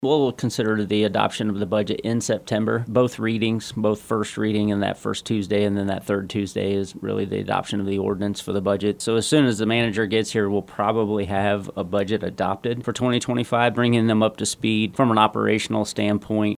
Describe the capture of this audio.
City officials noted on KMAN’s In Focus Tuesday morning they have received 44 applications for the vacancy.